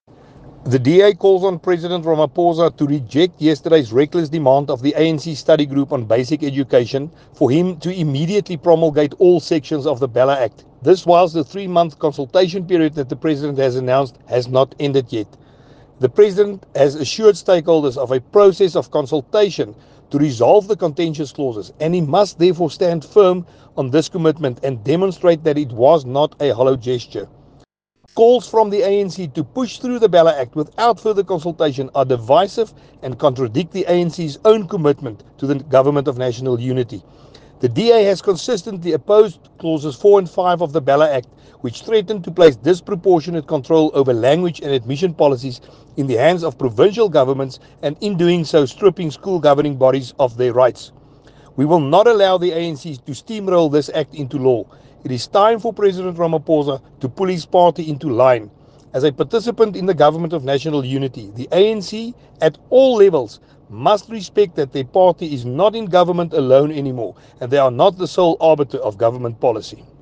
soundbite by Willie Aucamp MP.